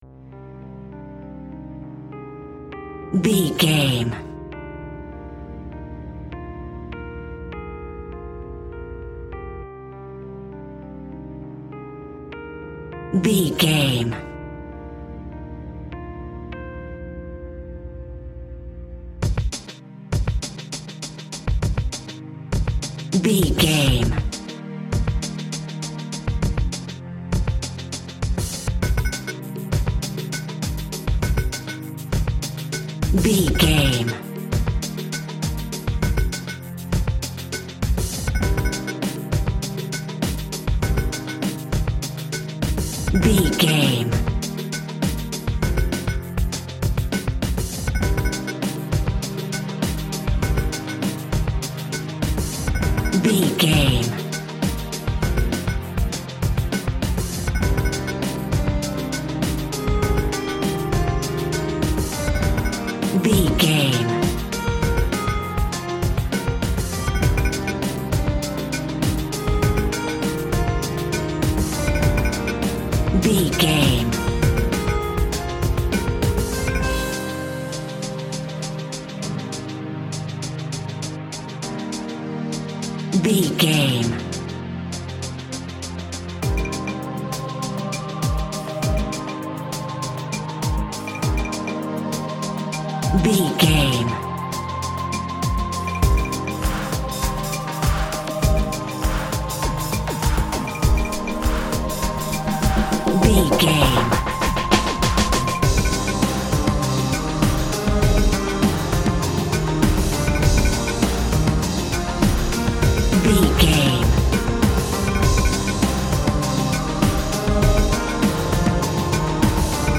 Aeolian/Minor
dark
futuristic
epic
groovy
synthesiser
strings
electric piano
drum machine
electronic
techno
trance
synth lead
synth bass